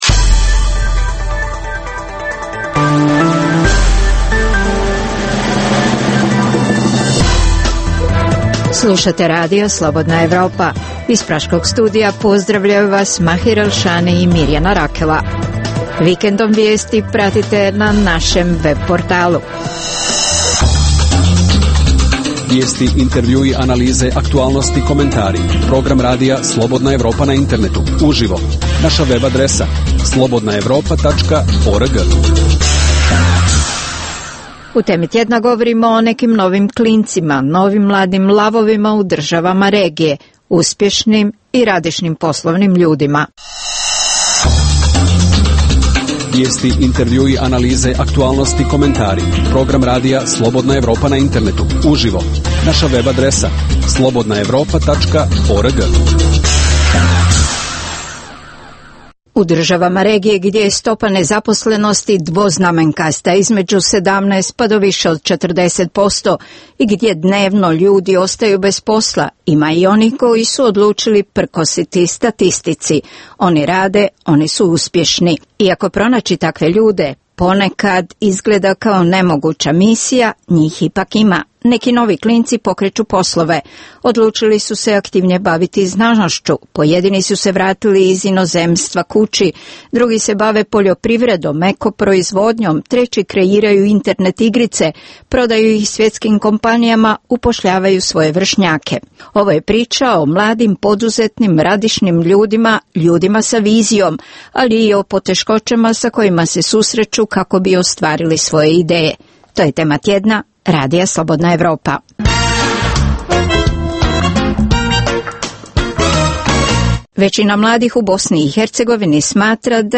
Za Radio Slobodna Evropa govore mladi poduzetnici iz BiH, Srbije, Hrvatske i Crne Gore.